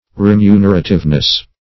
remunerativeness.mp3